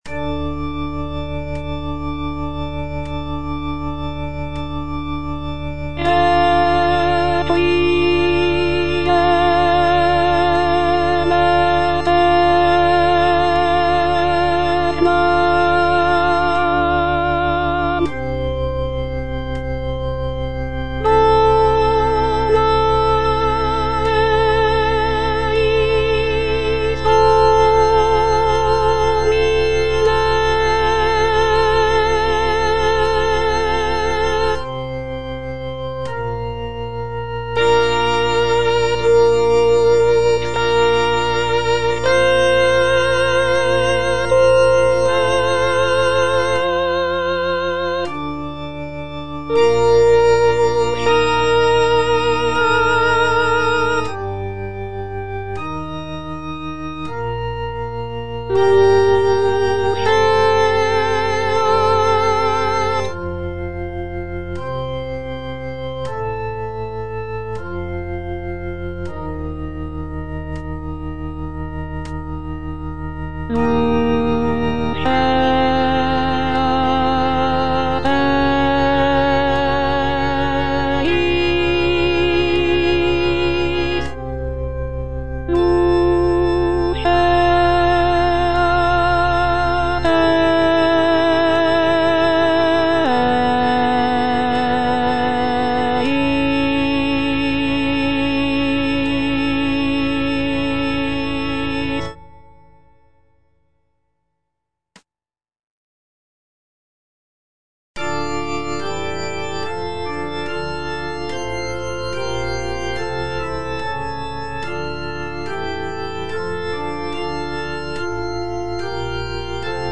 version with a smaller orchestra
Alto (Voice with metronome) Ads stop